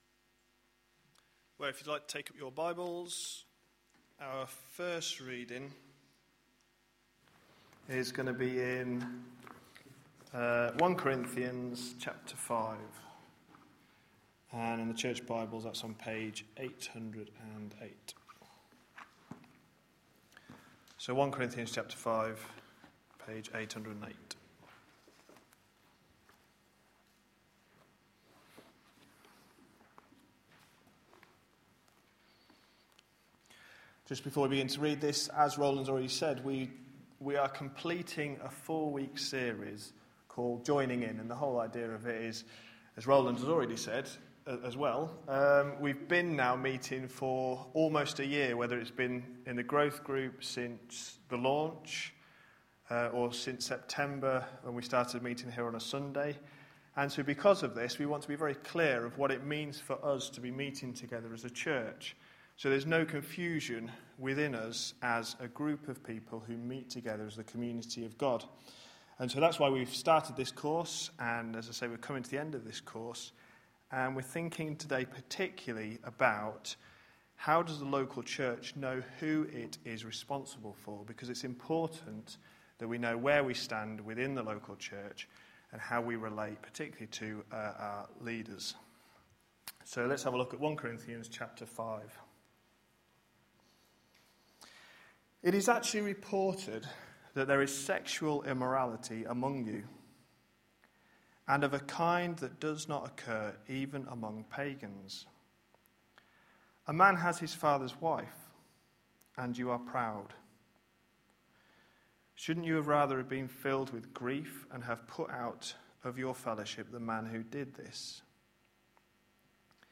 A sermon preached on 10th June, 2012, as part of our Joining In series.